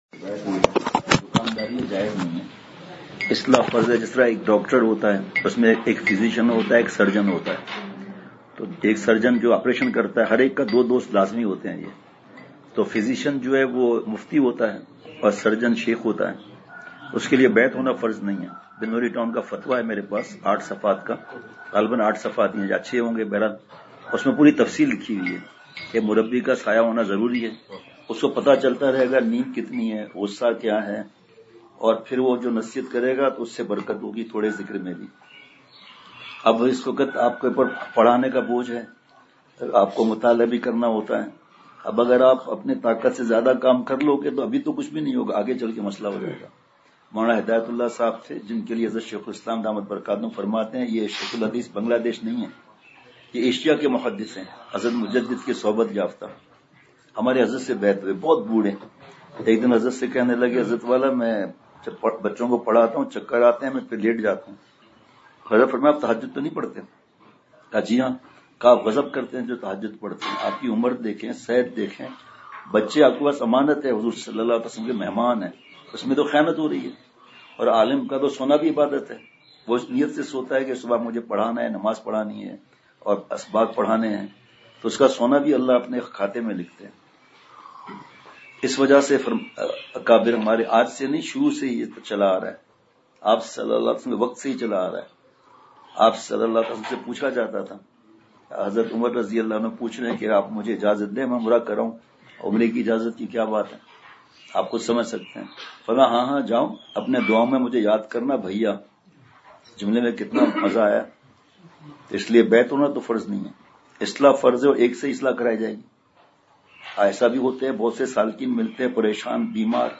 اصلاحی مجلس کی جھلکیاں بمقام۔ جامعہ تعلیم القرآن تختہ بند سوات
شام پانچ بجے * *مجلس بعد مسجد کی عصر کے بعد مجلس کے بعد در حجرہ حضرت والا دامت برکاتہم دین کا کام کرنے والوں کو تعلیم اعتدال اور تصوف کی حقیقت۔ ایک عالم نے کچھ سوالات کیے جن کا جواب ارشاد فرمایا مجلس کا دورانیہ 26:29